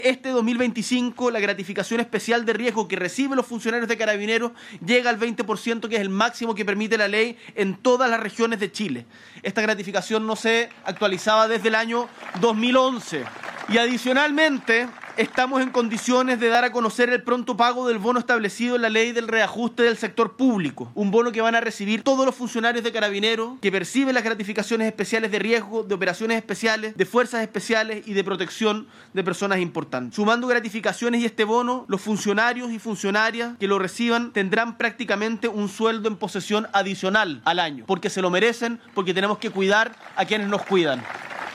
El anuncio se realizó durante la inauguración del Parque La Ermita en San Esteban, donde el Mandatario estuvo acompañado del ministro de Seguridad Pública, Luis Cordero, entre otras autoridades.